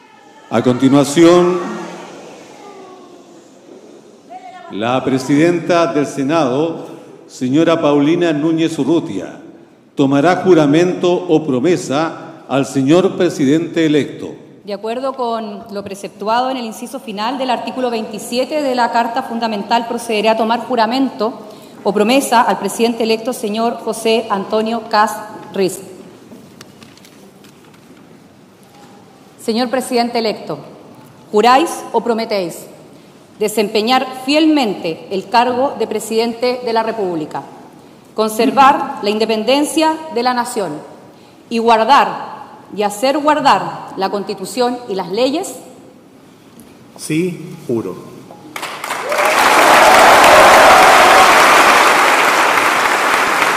En una ceremonia realizada este miércoles en el Salón de Honor del Congreso Nacional en Valparaíso, José Antonio Kast asumió oficialmente como Presidente de la República de Chile, iniciando su mandato tras el traspaso de poder con el ahora exmandatario Gabriel Boric.